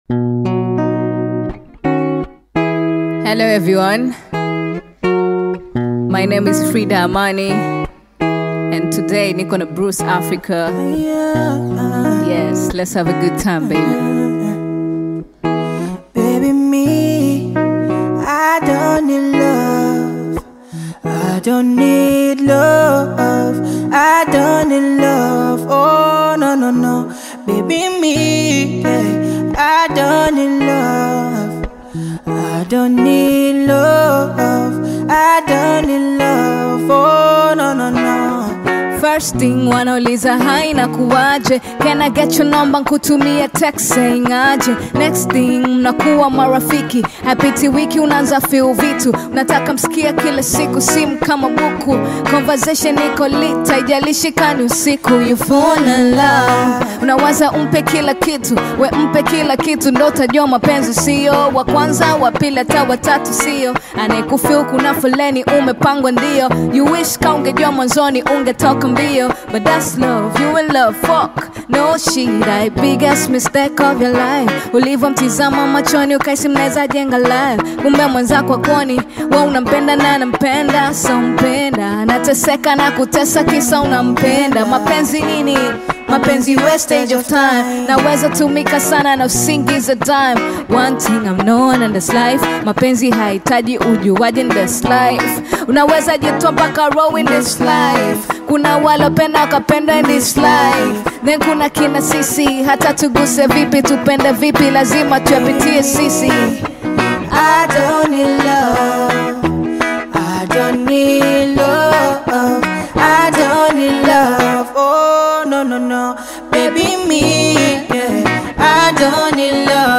distinctive rap style
” is likely to capture audiences with its energetic beats